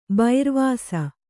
♪ bairvāsa